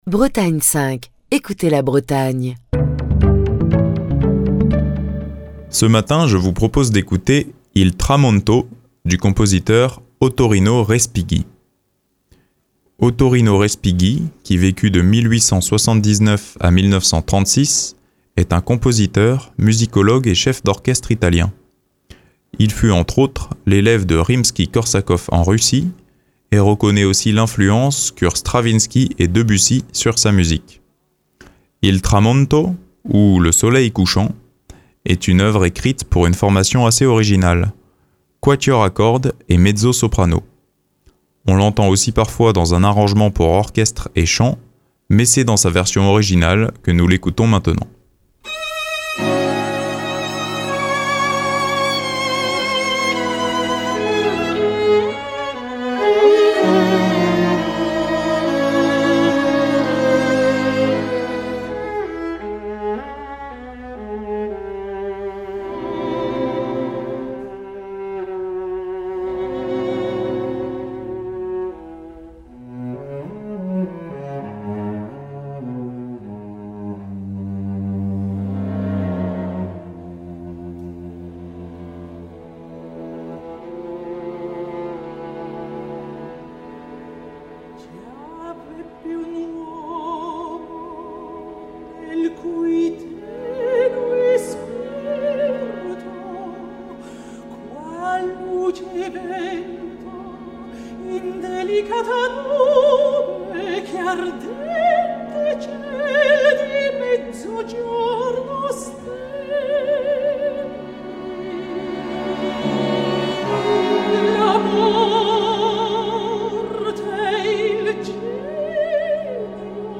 un quatuor à cordes britannique en activité depuis 1972.